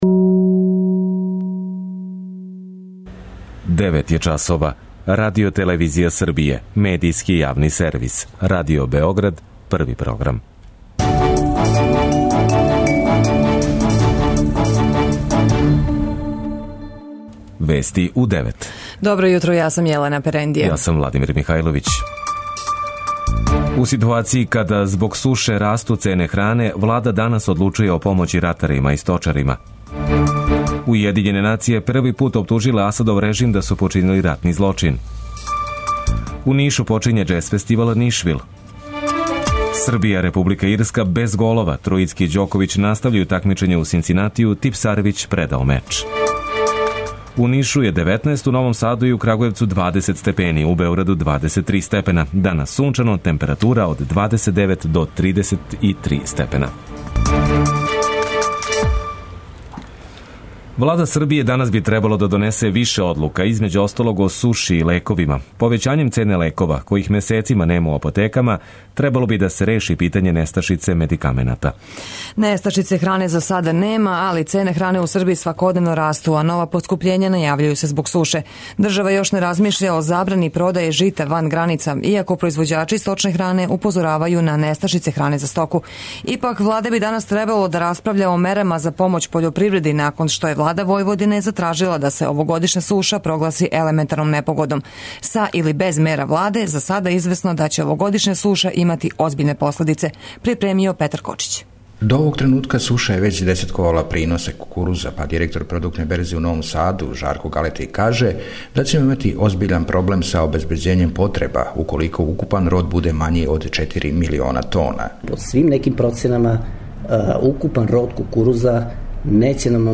преузми : 9.44 MB Вести у 9 Autor: разни аутори Преглед најважнијиx информација из земље из света.